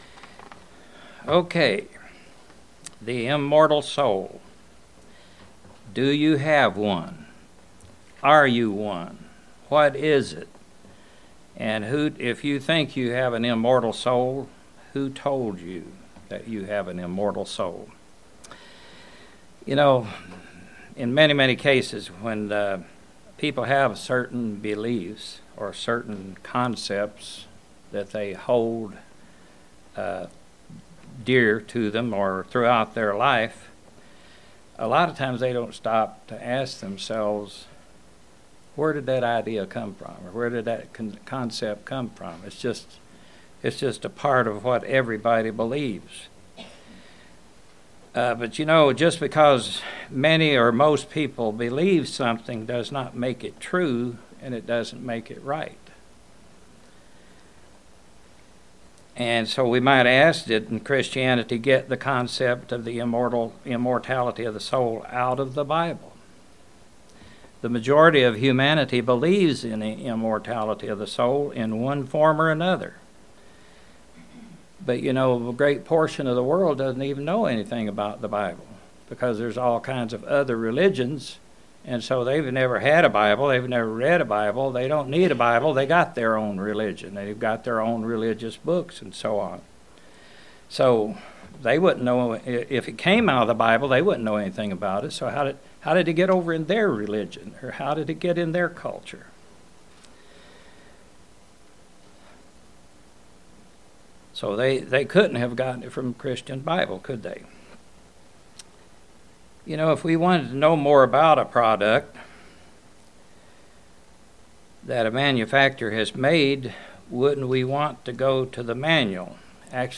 Sermons
Given in Kingsport, TN Knoxville, TN London, KY